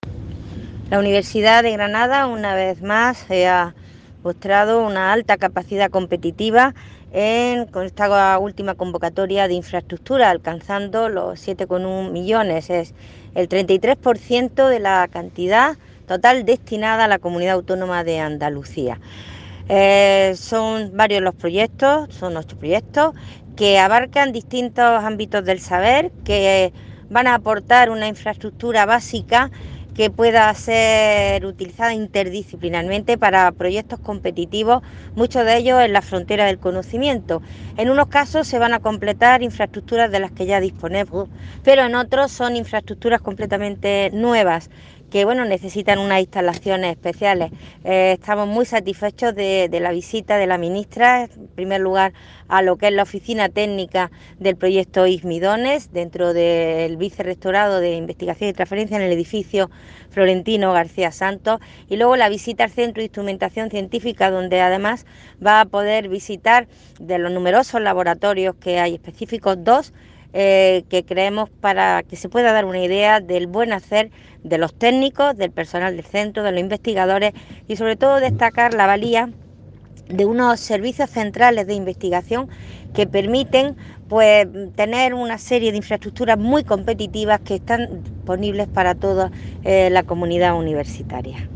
Audio con declaraciones de Pilar Aranda Ramírez, Rectora de la UGR
Audio-Pilar-Aranda-Escuzar.mp3